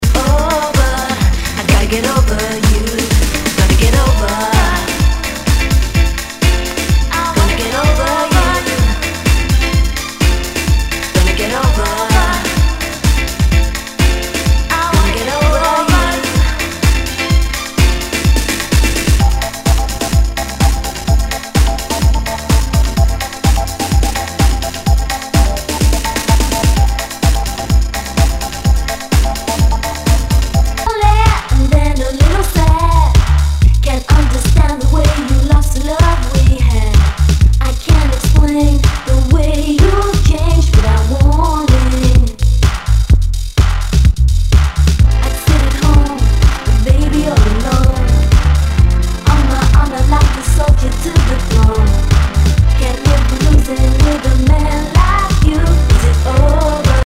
HOUSE/TECHNO/ELECTRO
ナイス！ディープ・ヴォーカル・ハウス・クラシック！